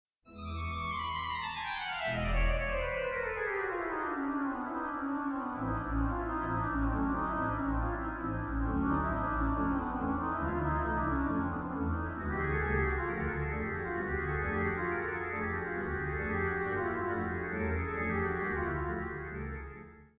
recorded  on the Christchurch Town Hall